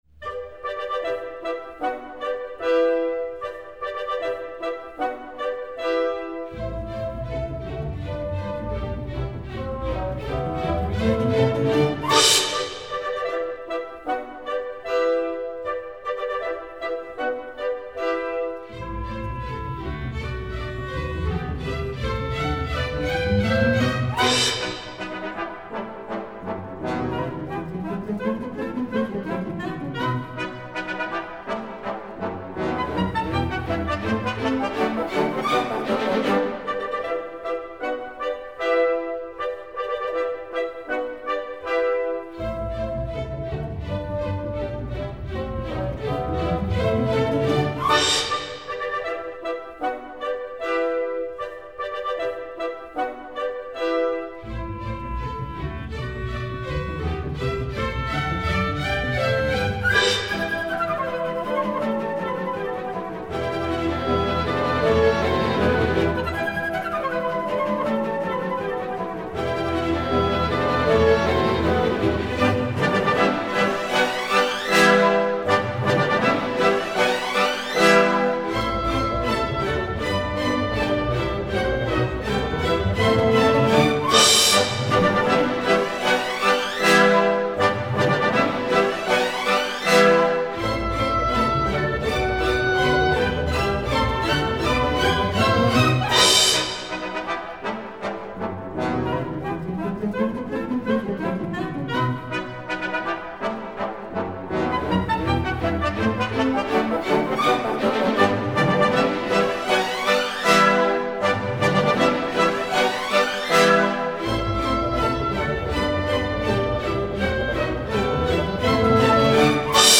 Ballet
Orquesta
Música clásica